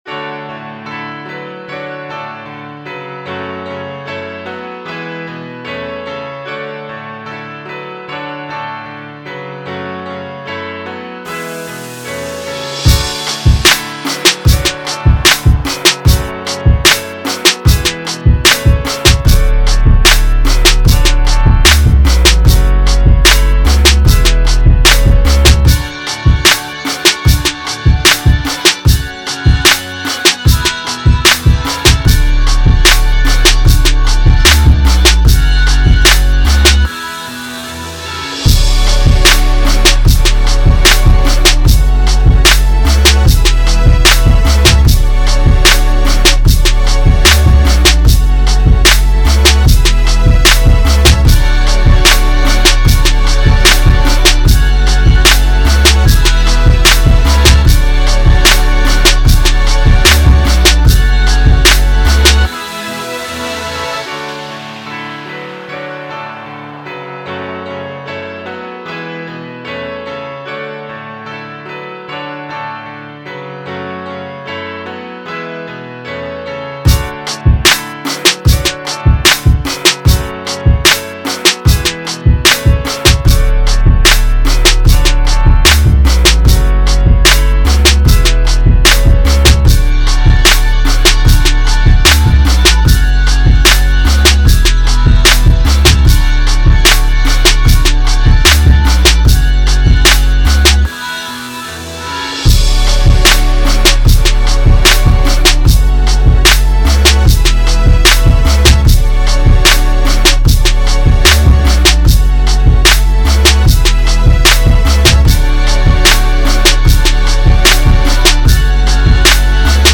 Rhythm and Blues Instrumentals